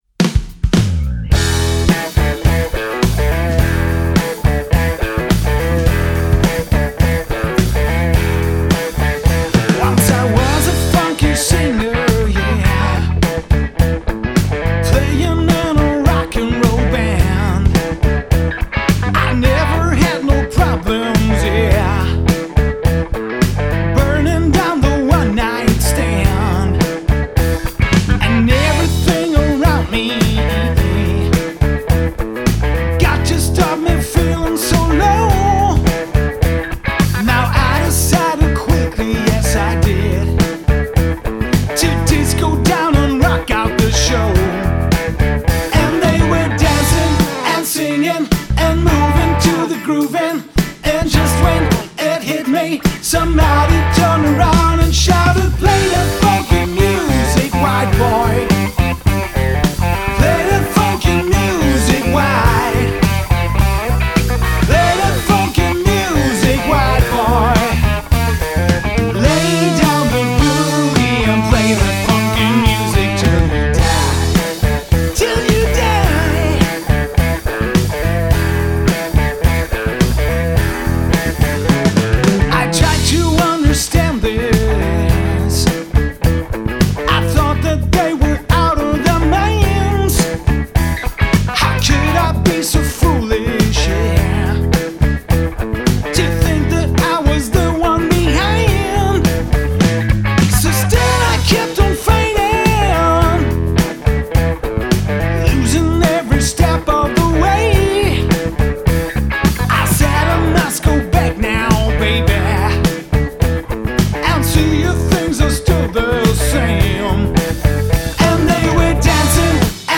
Trio
Rock & Funk mit Stil und noch mehr Energie.